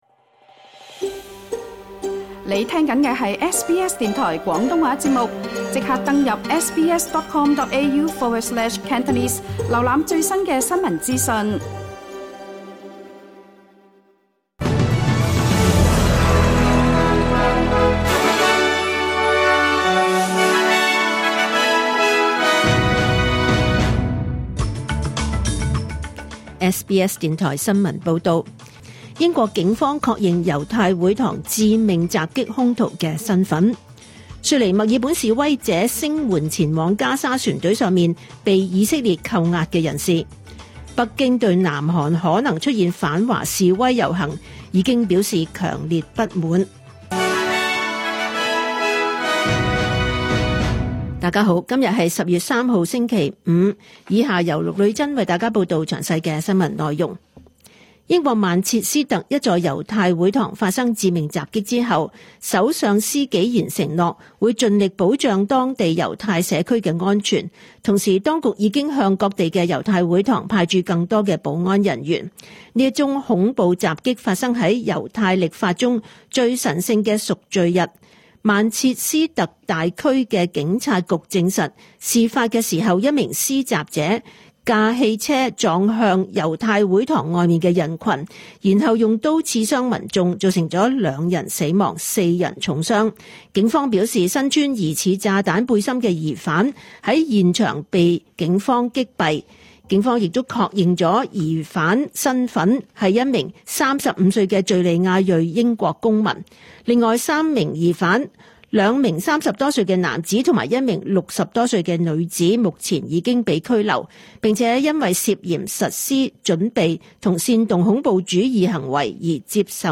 2025 年 10 月 3 日 SBS 廣東話節目詳盡早晨新聞報道。